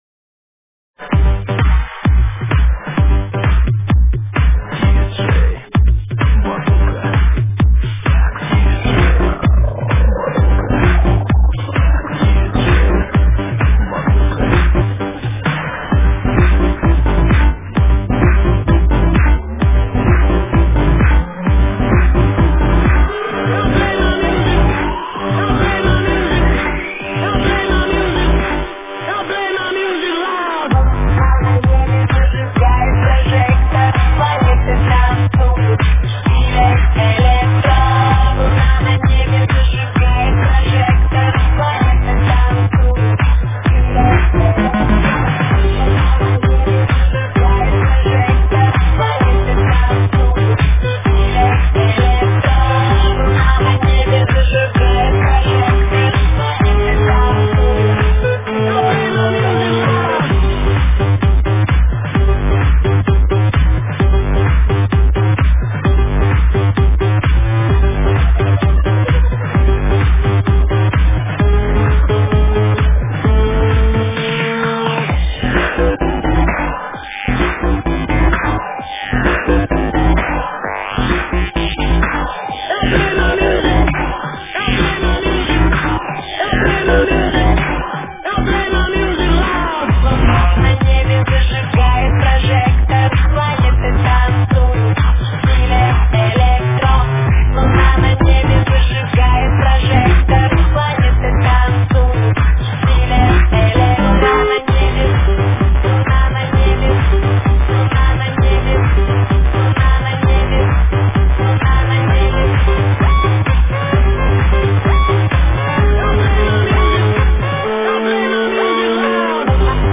Клубняк